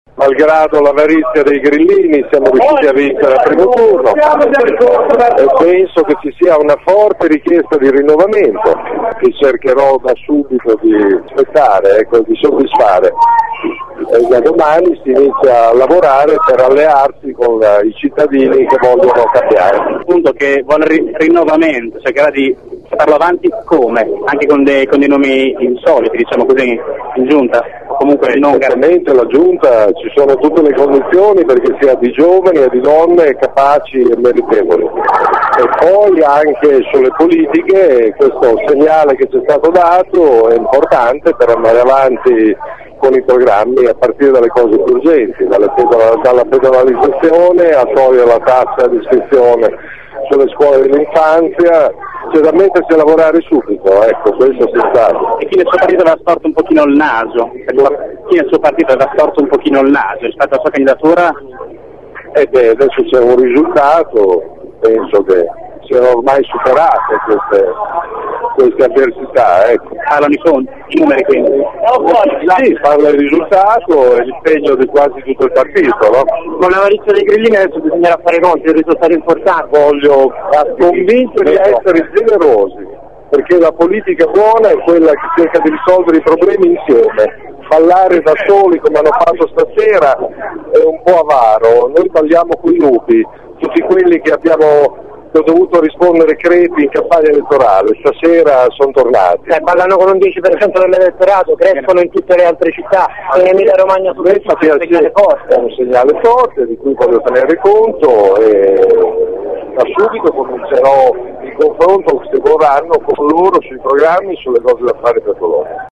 Merola è giunto al suo comitato elettorale solo a giochi  fatti, dopo le due di notte e ha parlato appena finito lo spoglio: “Unirò la città – ha detto – anche contro l’avarizia di chi festeggia da solo” .
Ascolta Virginio Merola nella prima dichiarazione da Sindaco di Bologna